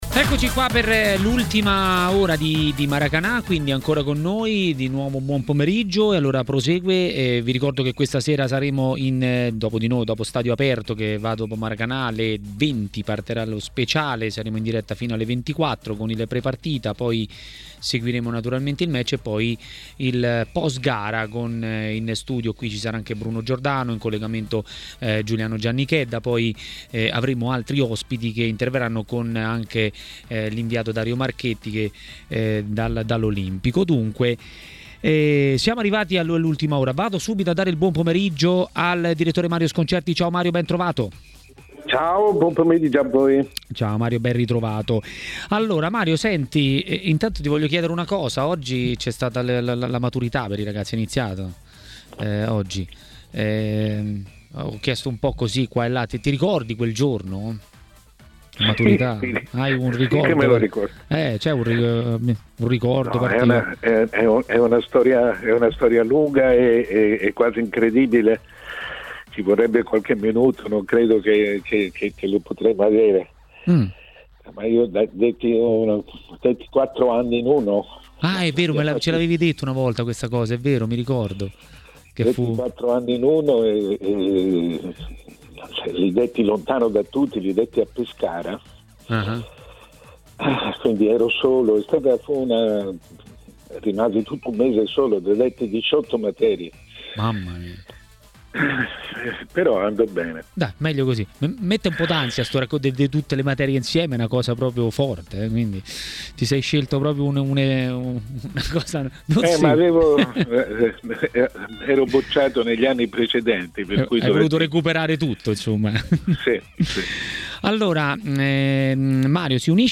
A parlare dei temi di giornata a TMW Radio, durante Maracanà, è intervenuto mister Gianni De Biasi.